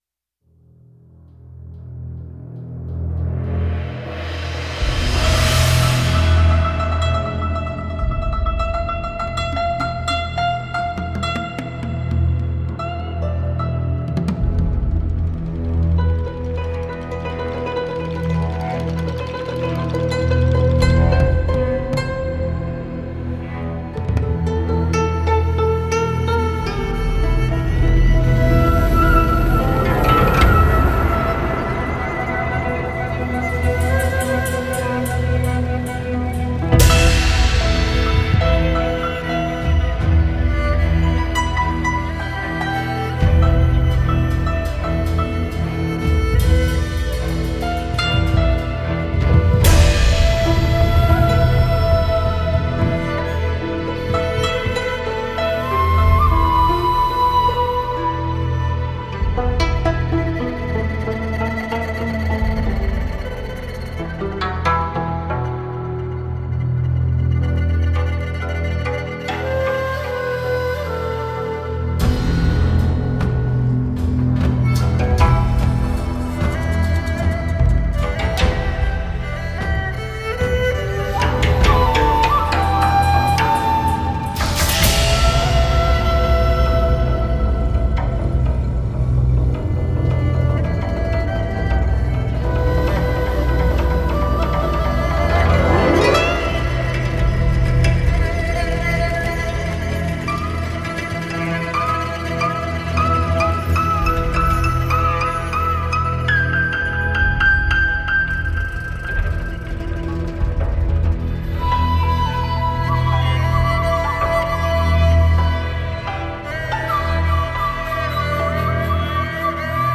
复杂而详细的Kontakt样本库，具有中国古筝的真实而优美的音色！
指弹拨和拾音技术
谐波和弯曲（整个/半音）
古筝也被称为中国古筝，是一种古老的乐器，由21根弦组成，伸展在装饰性的木制长琴身上，并带有可调节的琴桥。